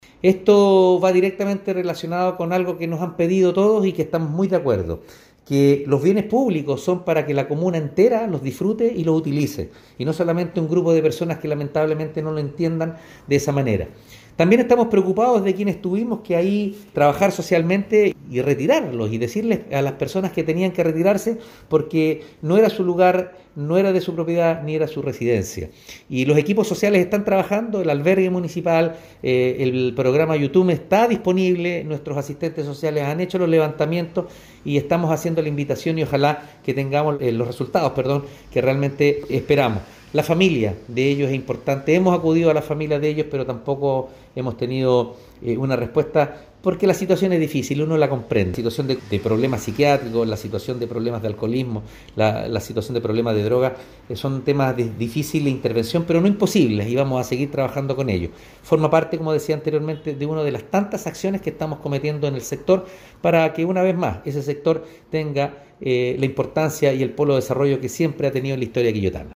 Alcalde-Oscar-Calderon-Sanchez-1-2.mp3